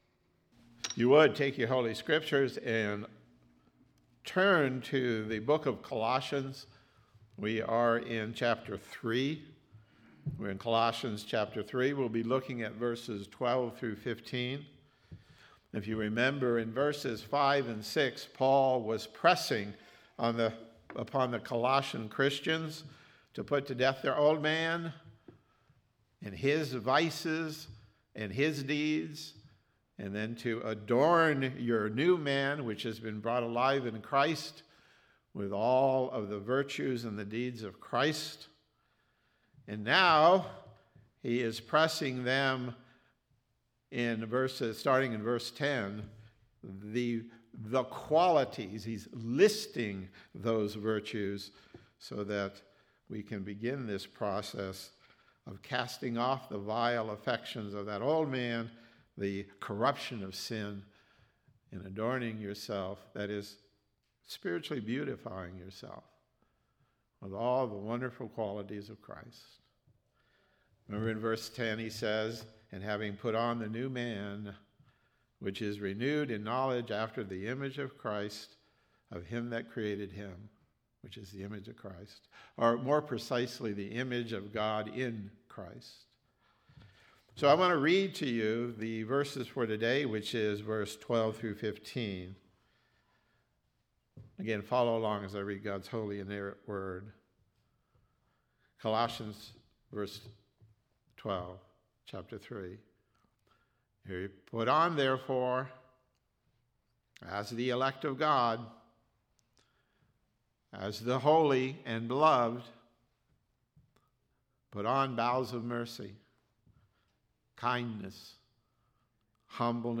Sermons | Reformed Presbyterian Church of Ocala